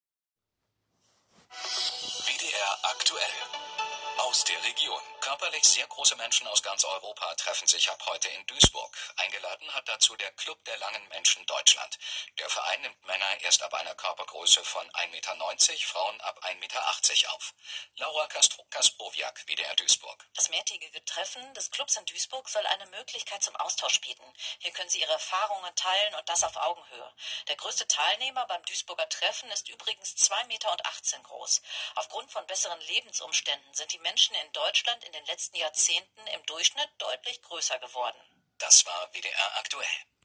25.05.2025 WDR2 radio news spot